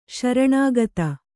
♪ śaraṇāgata